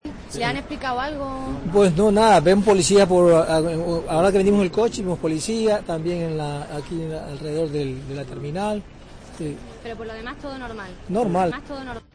COPE habla un pasajero del avión